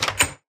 door_open.ogg